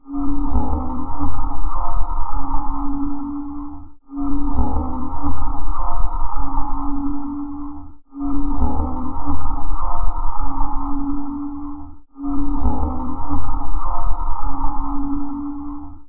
SONIDO DE MIEDO SCARY AMBIANCE SOUND
Ambient sound effects
Sonido_de_miedo_Scary_Ambiance_Sound.mp3